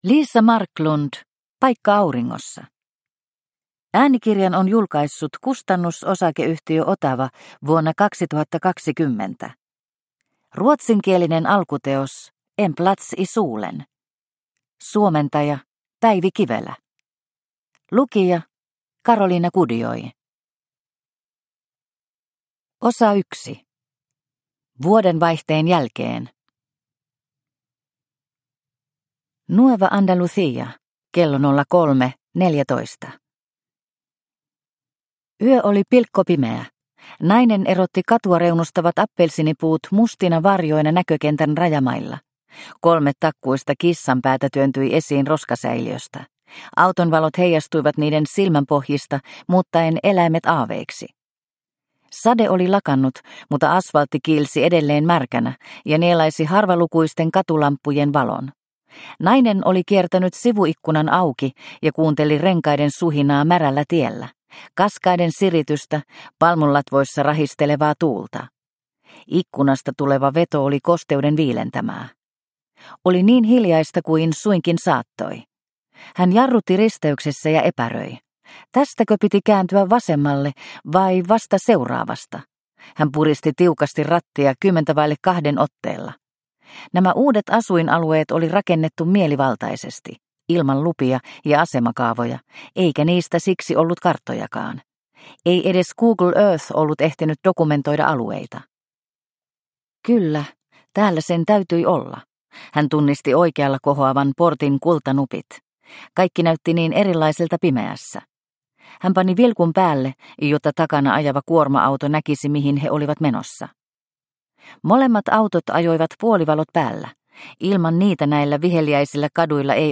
Paikka auringossa – Ljudbok – Laddas ner